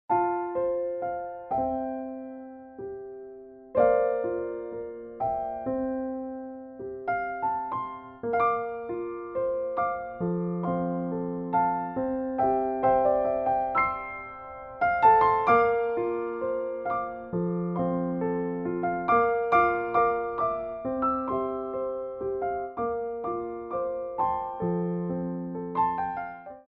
Port de Bras 1
4/4 (8x8)